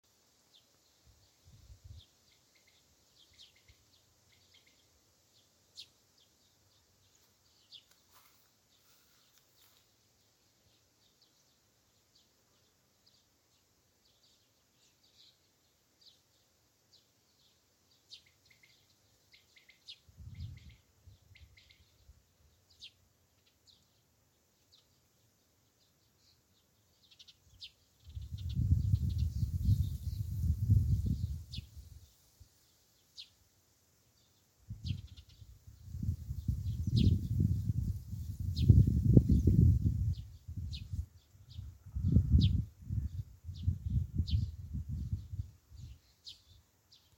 Common Quail, Coturnix coturnix
StatusSinging male in breeding season
NotesAuzu laukā